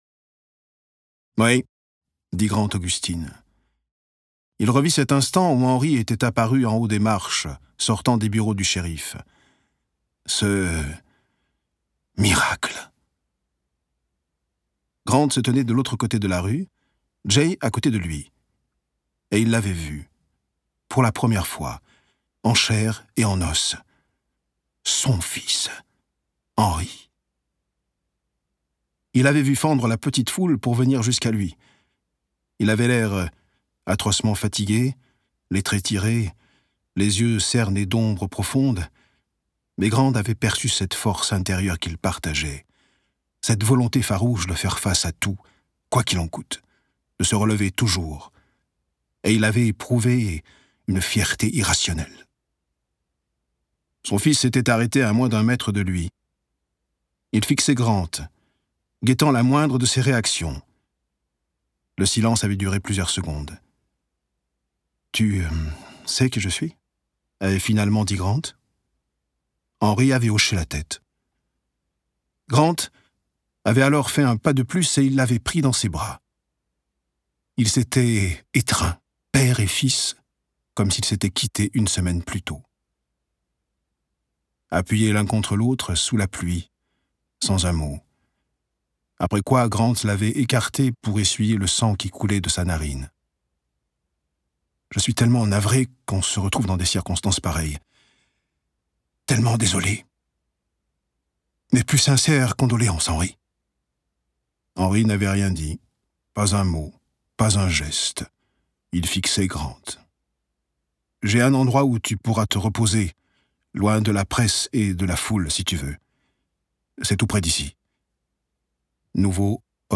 Voix off
Démo Voix
Voix 45 - 65 ans - Ténor